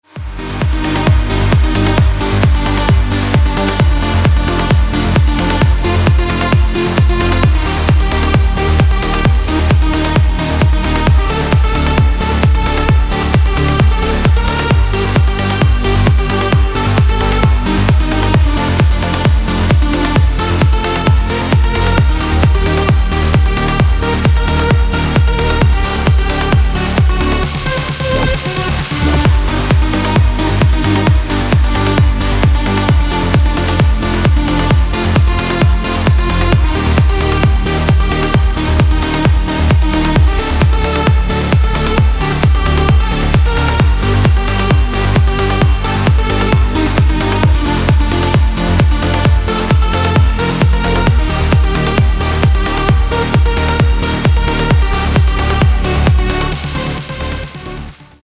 • Качество: 256, Stereo
громкие
remix
dance
Electronic
EDM
электронная музыка
без слов
Trance